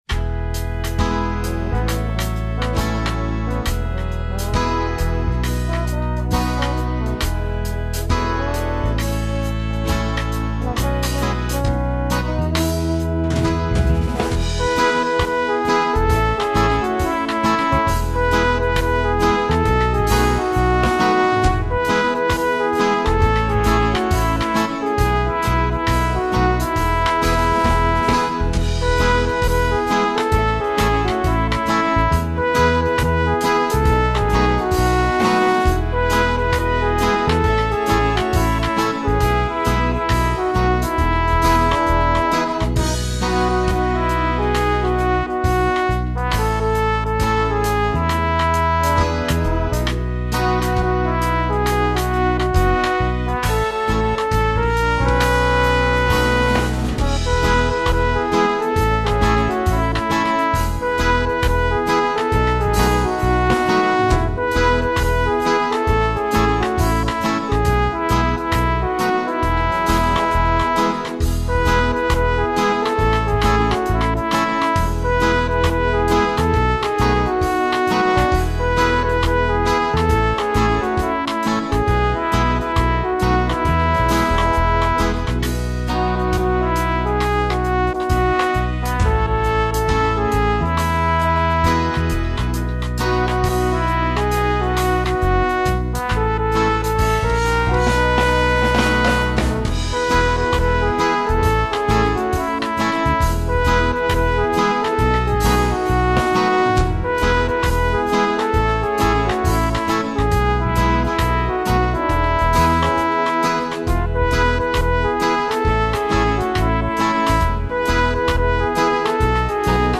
This is a fun, if lyrically simplistic, reggae song written by a committee.
These people are commendably joyous while struggling with the syncopation, but throw in a key change to mix things up.